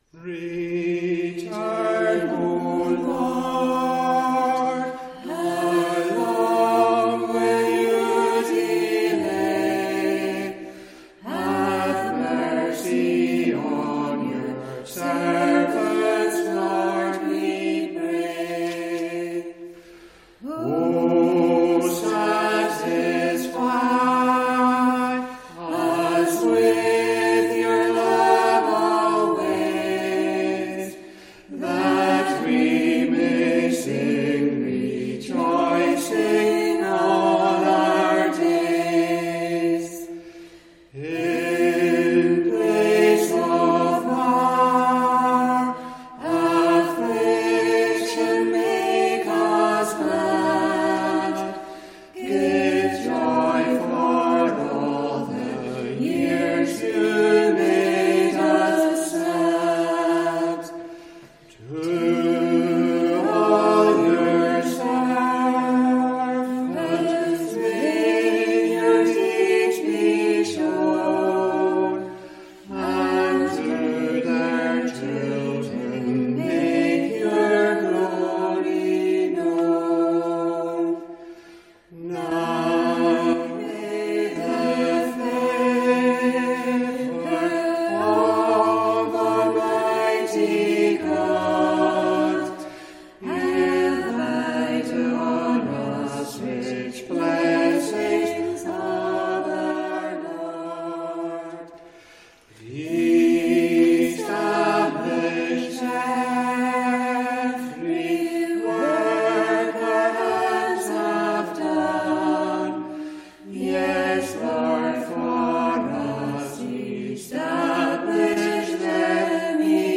Congregational Psalm Singing
Recorded during the first lockdown when church services went on-line, with five of us singing live at a microphone built for one.